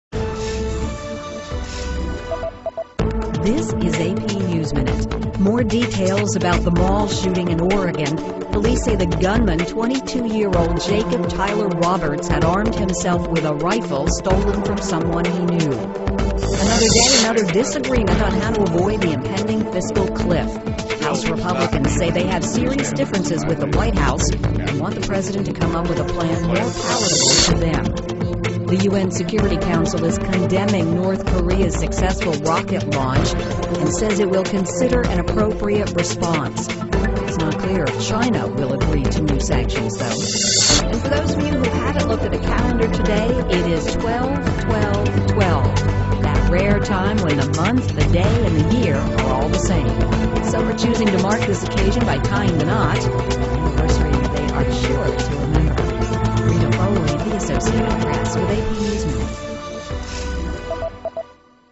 在线英语听力室美联社新闻一分钟 AP 2012-12-16的听力文件下载,美联社新闻一分钟2012,英语听力,英语新闻,英语MP3 由美联社编辑的一分钟国际电视新闻，报道每天发生的重大国际事件。电视新闻片长一分钟，一般包括五个小段，简明扼要，语言规范，便于大家快速了解世界大事。